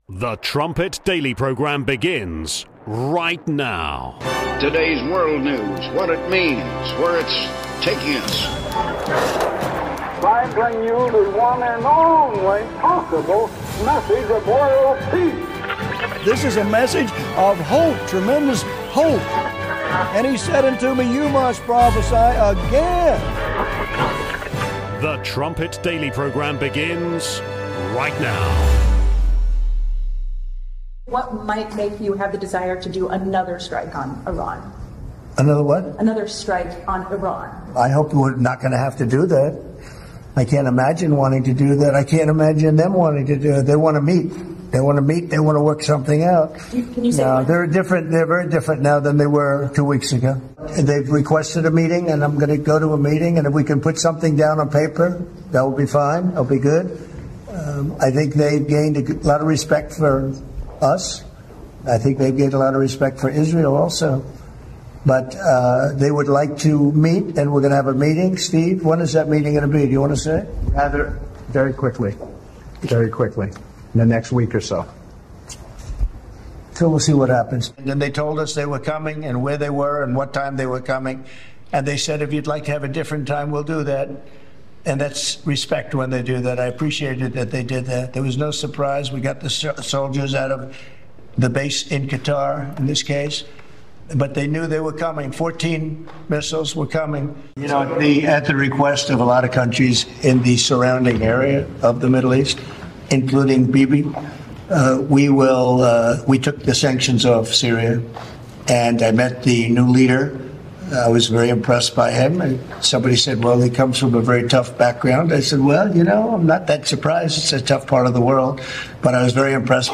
27:00 Trumpet Daily Interview: Michael Oren (28 minutes)